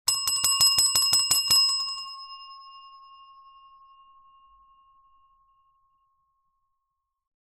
campainha.mp3